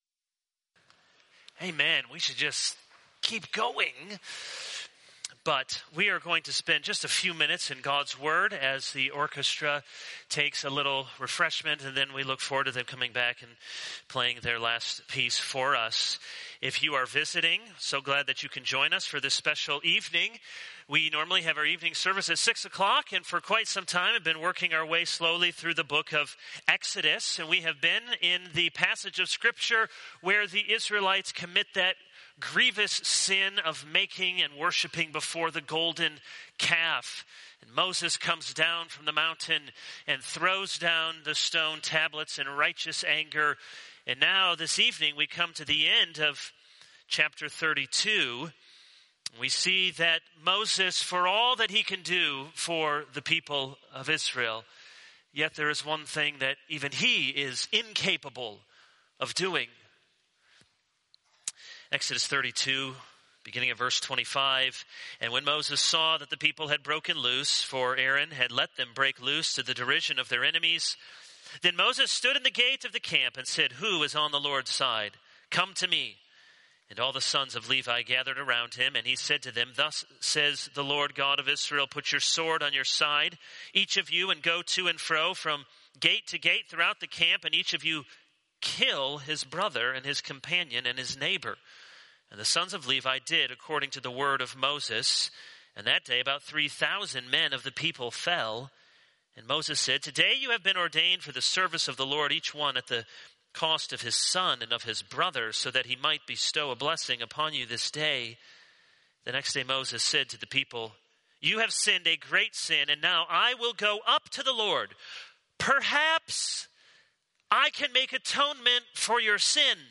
This is a sermon on Exodus 32:25-35.